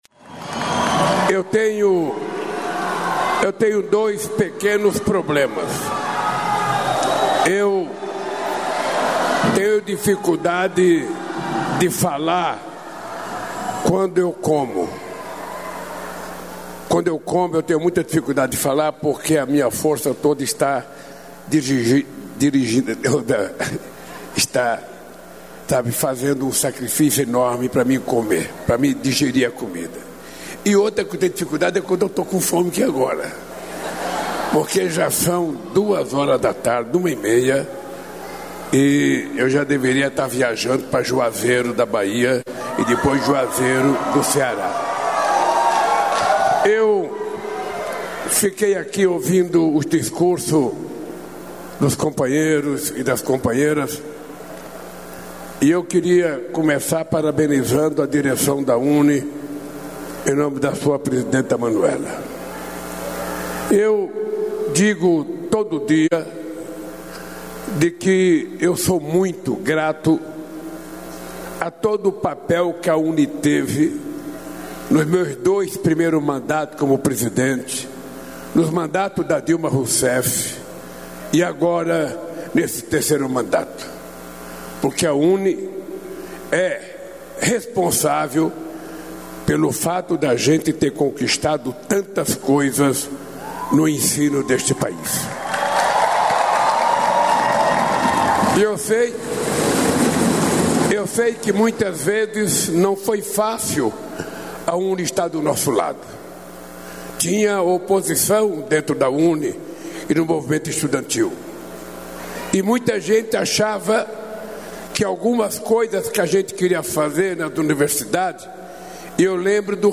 O presidente Luiz Inácio Lula da Silva concedeu coletiva à imprensa, durante encerramento da cúpula do BRICS, nesta segunda-feira (7), no Rio de Janeiro (RJ). Na ocasião, Lula fez um balanço do evento e comentou papel do bloco.